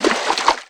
High Quality Footsteps
STEPS Water, Stride 01.wav